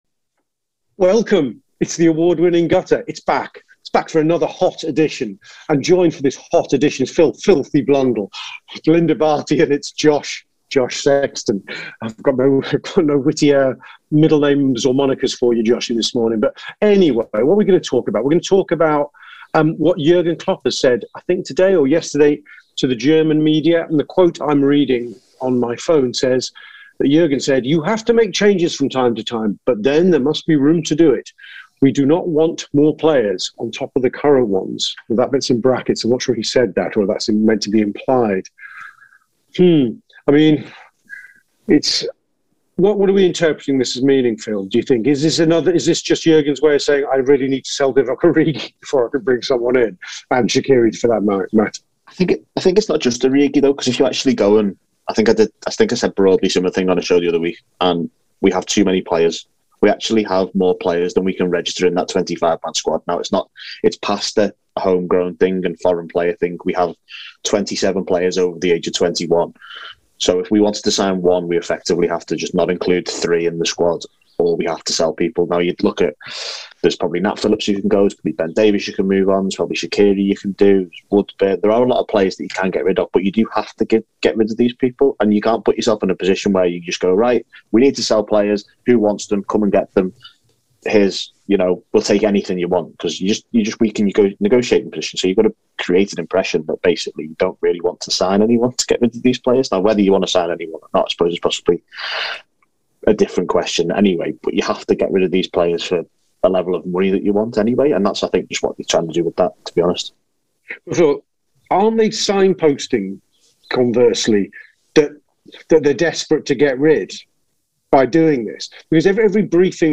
The panel discusses the various scenarios unfolding for Liverpool this transfer window.
Below is a clip from the show – subscribe to TAW Player for more on Liverpool and the transfer market…